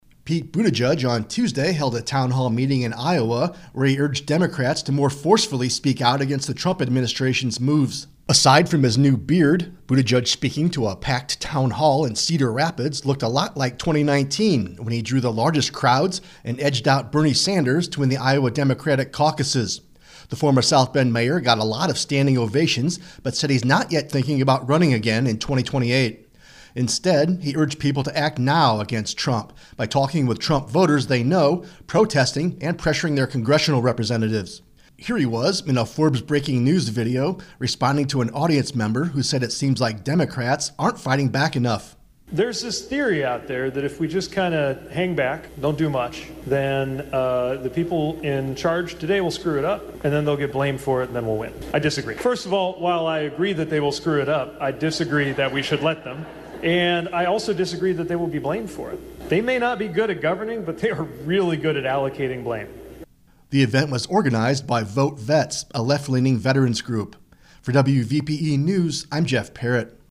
Listen in Popup Download MP3 Comment Pete Buttigieg speaks out against the Trump administration's moves so far this year Tuesday at a town hall in Cedar Rapids, Iowa organized by VoteVets, a left-leaning veterans group.
The former South Bend mayor got a lot of standing ovations but said he’s not yet thinking about running again in 2028.